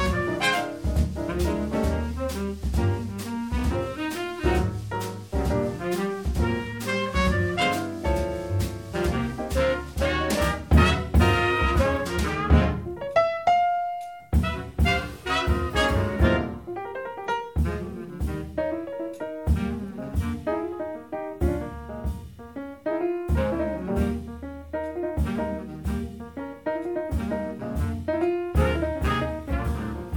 "templateExpression" => "Rhythm'n'blues, soul"